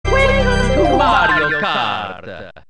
welcome-to-mario-kart_1-mp3cut.mp3